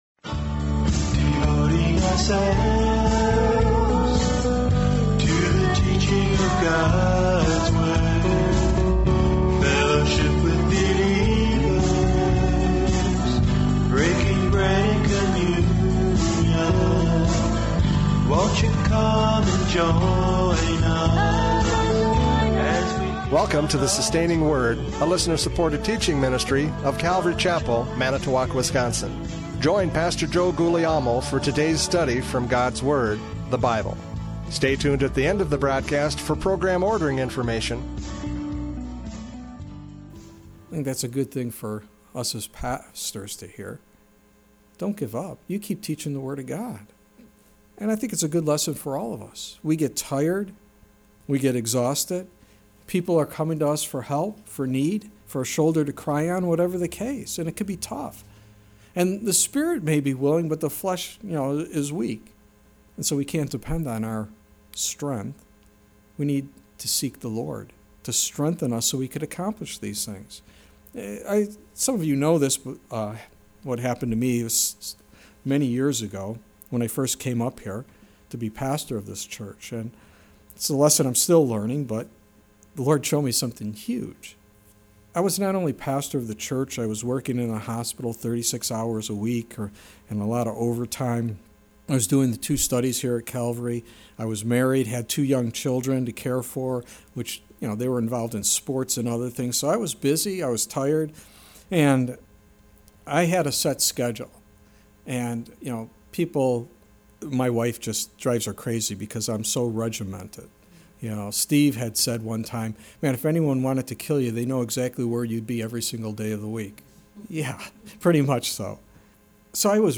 John 6:1-14 Service Type: Radio Programs « John 6:1-14 Spiritual Hunger!